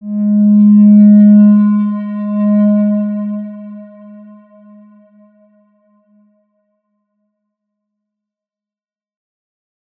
X_Windwistle-G#2-pp.wav